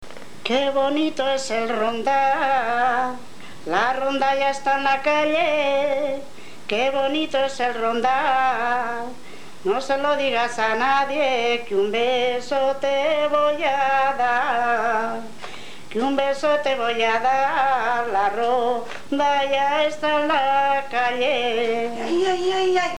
Jotas y canciones de ronda